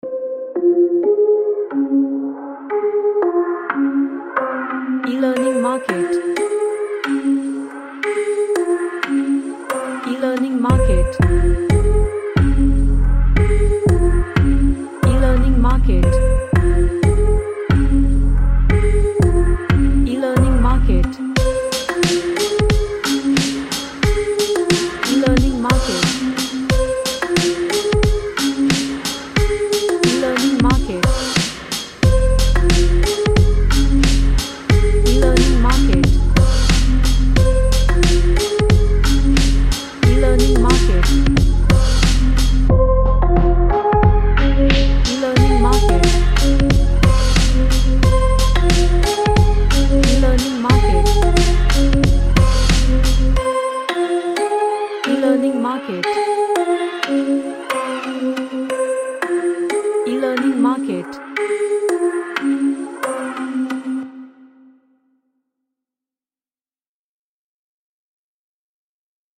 A lofi track with old hip hop vibe
Strange / Bizarre